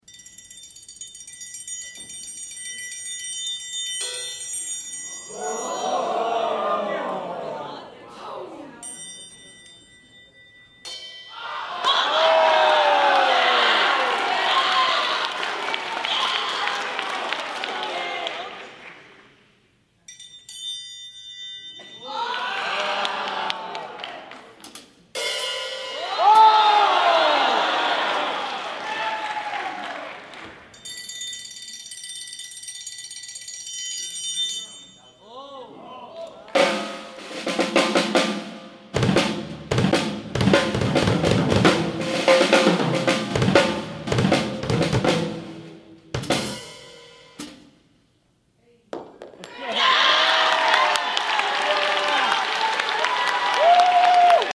Triangle VS Drumset Battle!!! sound effects free download